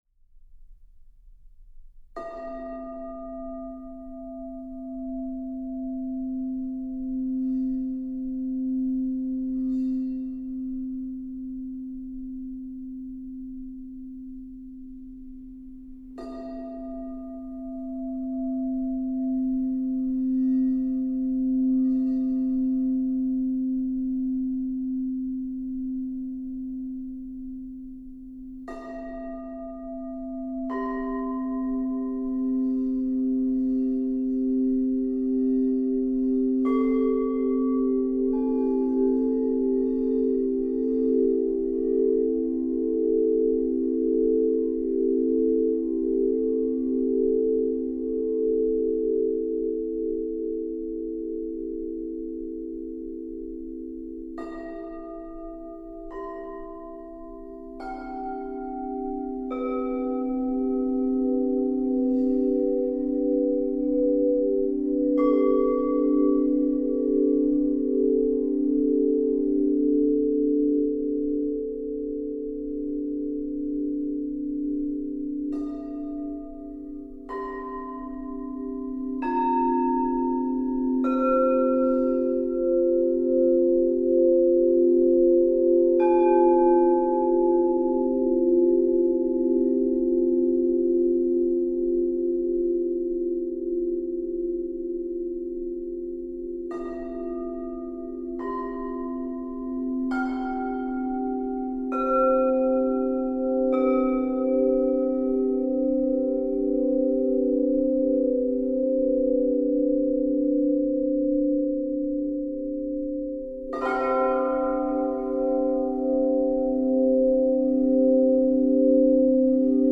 SATB Chorus with Divisi, Cello, & Crystal Singing Bowls